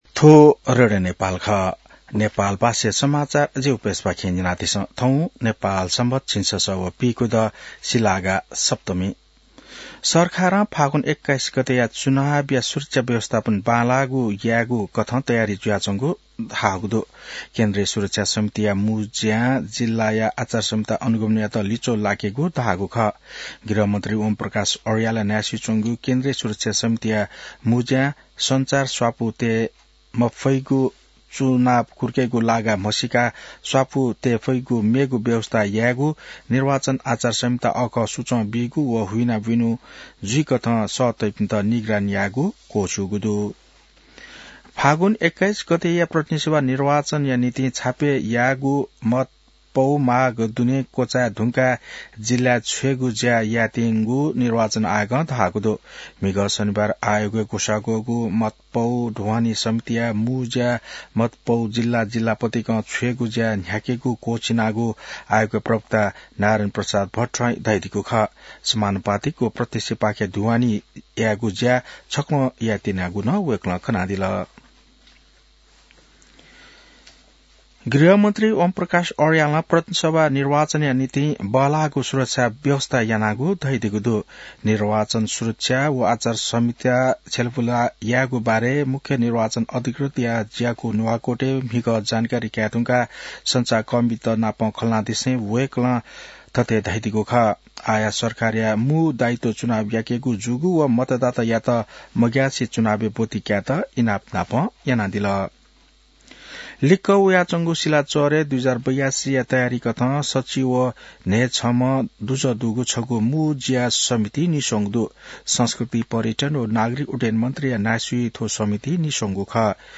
An online outlet of Nepal's national radio broadcaster
नेपाल भाषामा समाचार : २५ माघ , २०८२